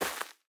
Minecraft Version Minecraft Version snapshot Latest Release | Latest Snapshot snapshot / assets / minecraft / sounds / block / hanging_roots / step6.ogg Compare With Compare With Latest Release | Latest Snapshot
step6.ogg